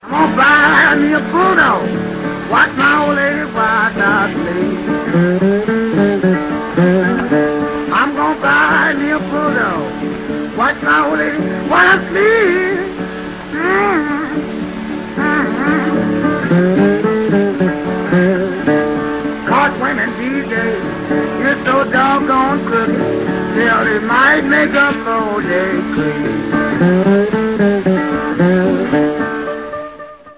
шедевром гитарного стиля “боттлнек”